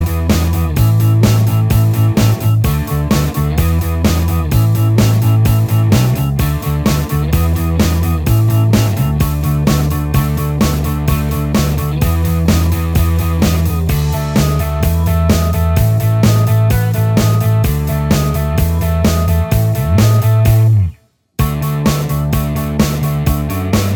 Minus Lead Guitar Rock 3:32 Buy £1.50